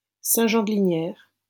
-Saint-Jean-de-Linières.wav Audio pronunciation file from the Lingua Libre project.